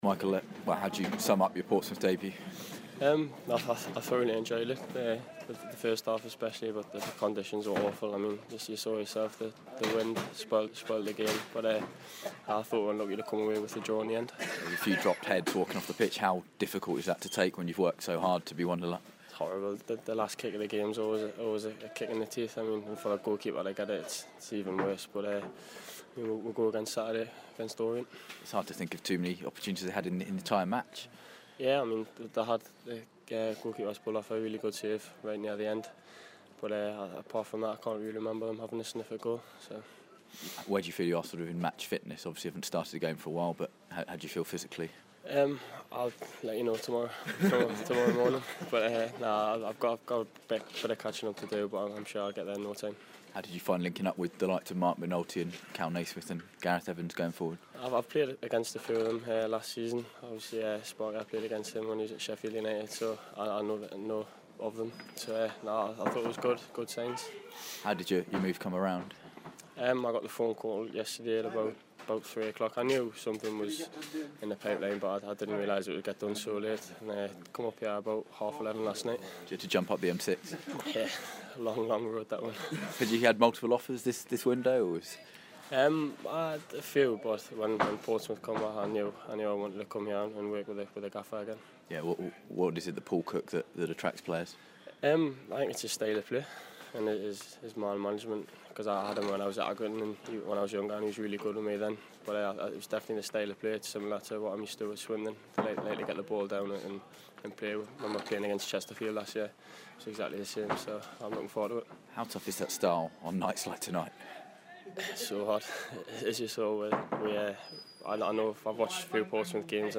speaking after their 1-1 draw at Morecambe